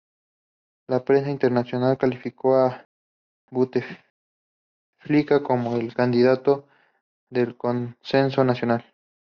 Read more consensus Frequency 23k Hyphenated as con‧sen‧so Pronounced as (IPA) /konˈsenso/ Etymology Borrowed from Latin cōnsēnsus In summary Borrowed from Latin cōnsēnsus.